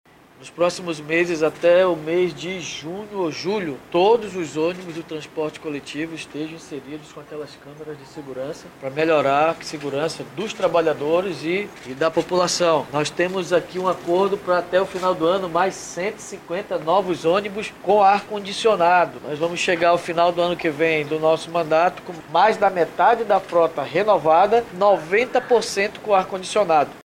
SONORA03_DAVI-ALMEIDA.mp3